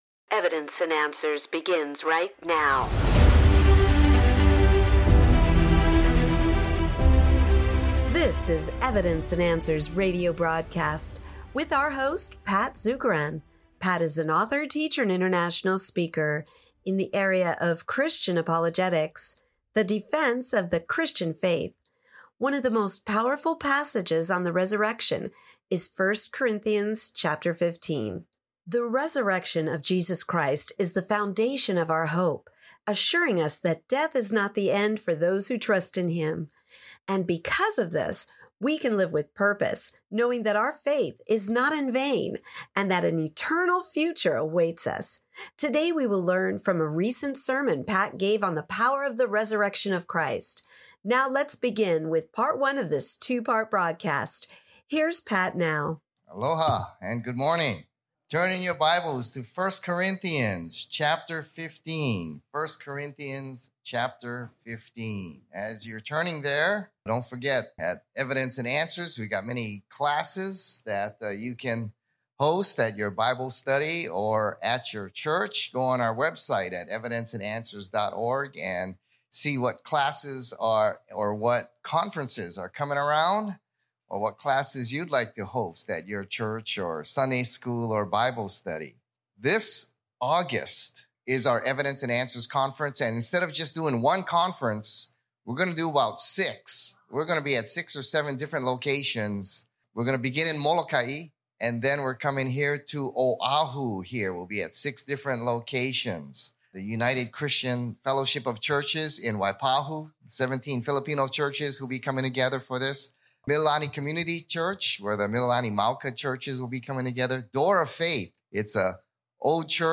he preaches from 1 Corinthians 15:20-32 on the Power of the Resurrection.